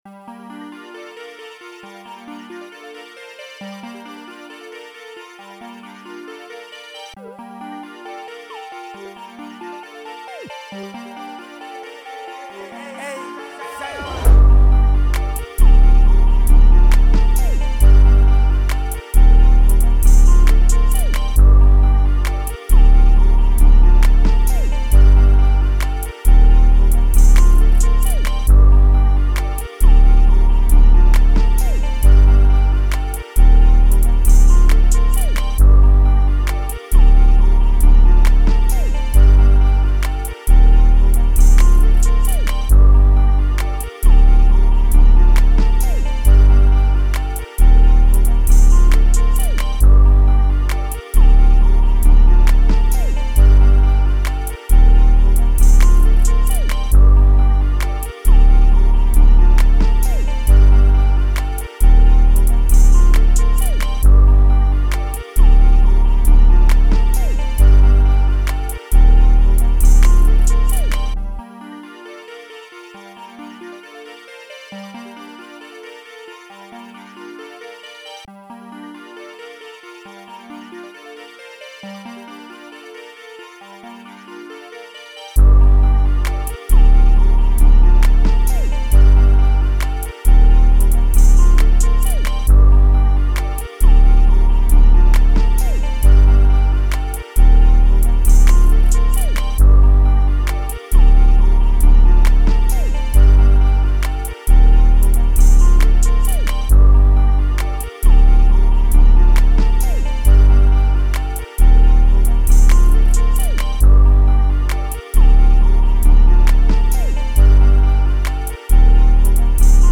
135 G Minor